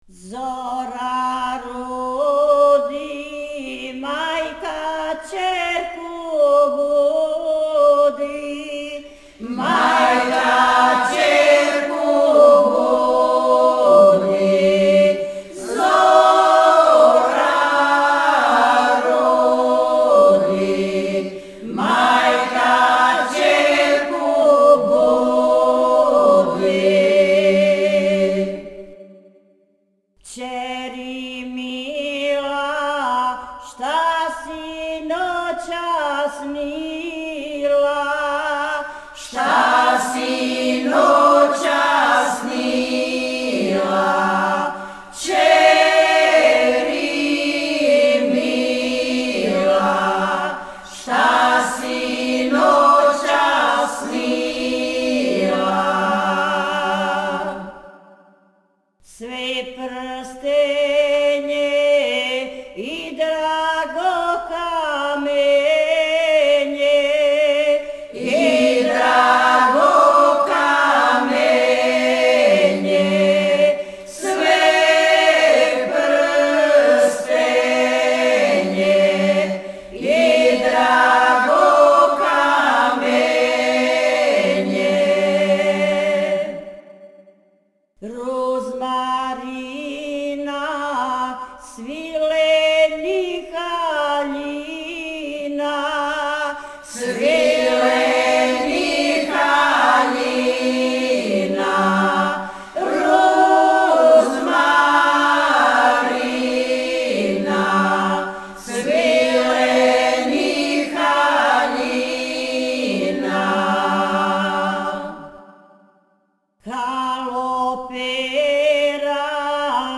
Група "Стеван Книћанин", Кнић
Порекло песме: Шумадија